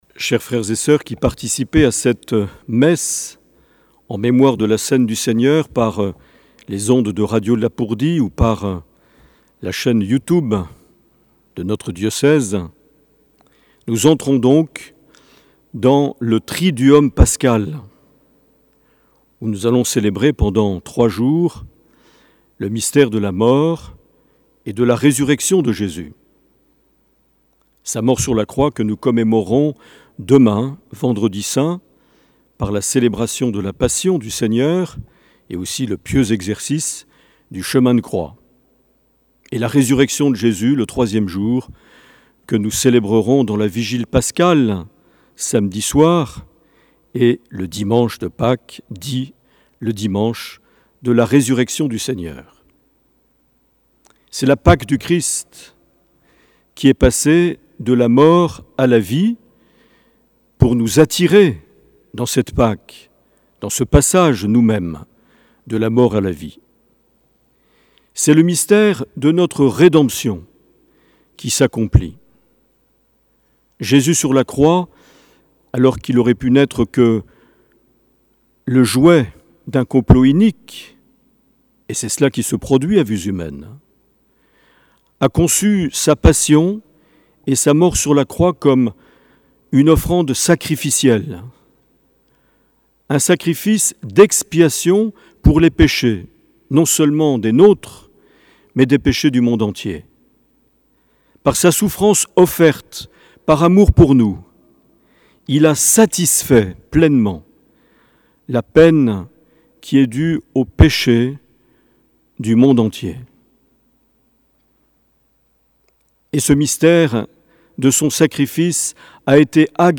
09 avril 2020 - Jeudi Saint - Cathédrale de Bayonne
Les Homélies
Une émission présentée par Monseigneur Marc Aillet